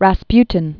(răs-pytĭn, rə-sptyĭn), Grigori Efimovich 1869-1916.